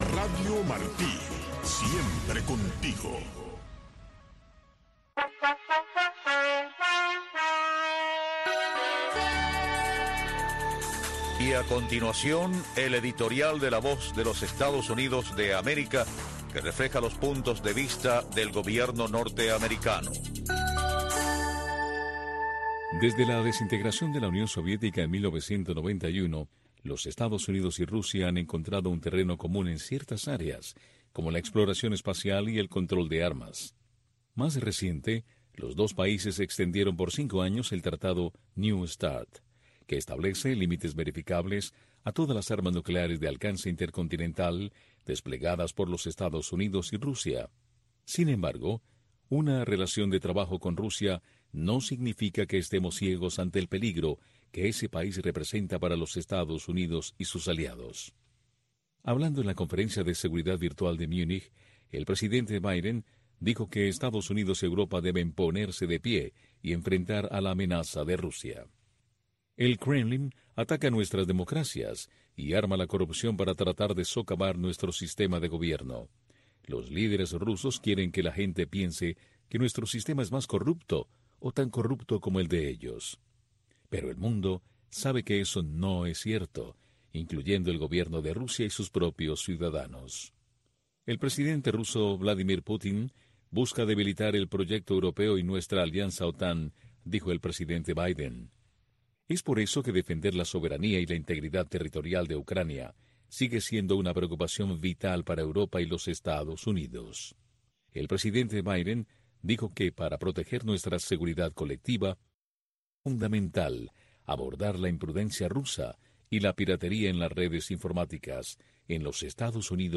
una revista de entrevistas